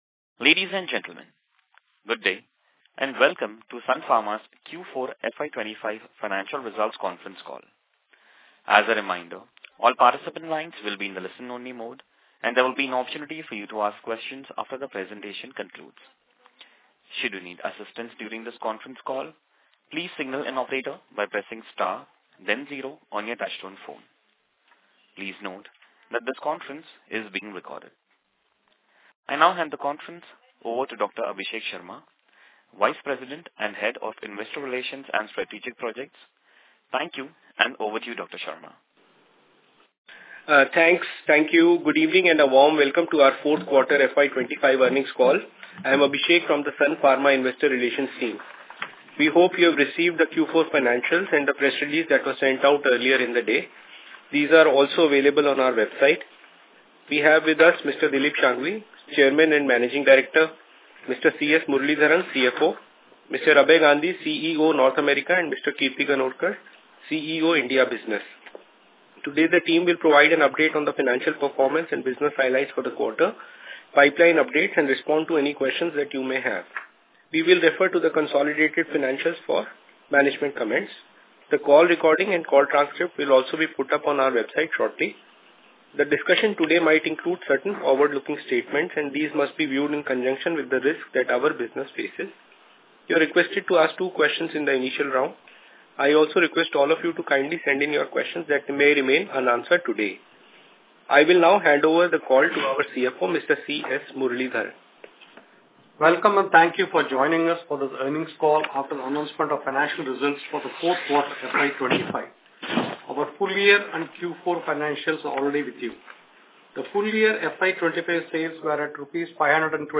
Earnings Call Audio Recording